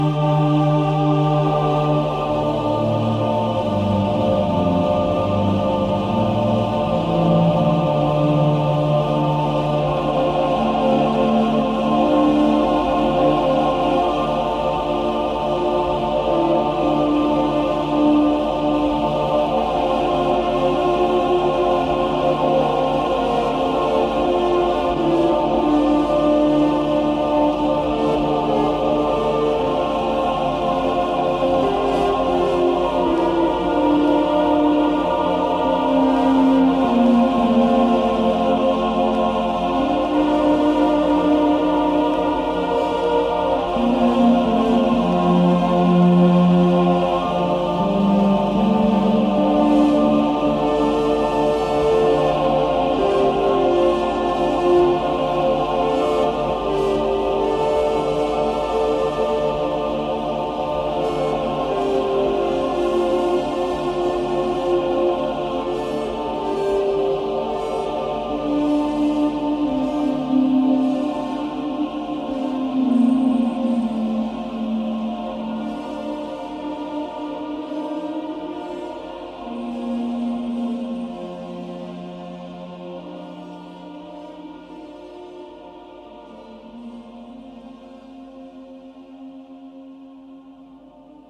La atmosférica, misticista y ensoñadora música